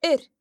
When R is not the first letter in a word and occurs next to e or i, it is considered slender, and can be heard in oir (an edge):